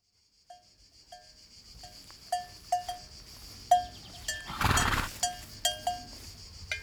horsebell.wav